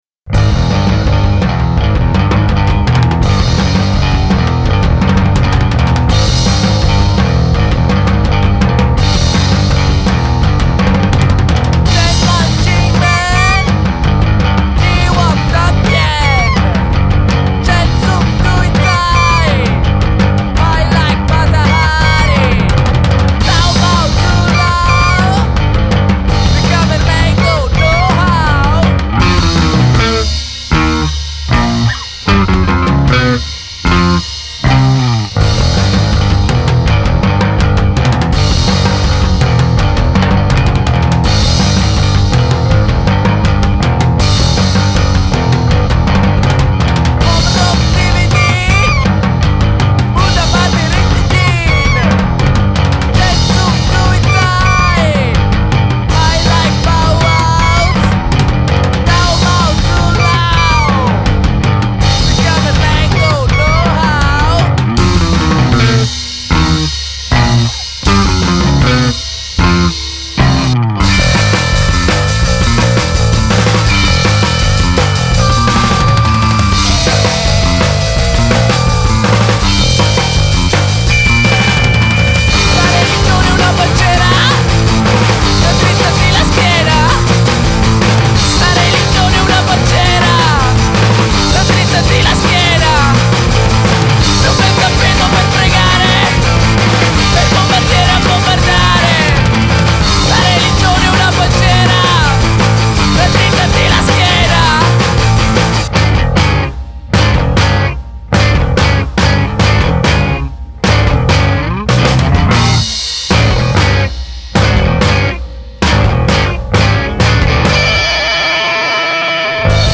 genre: hard-wheat rock
The mechanical psychedelia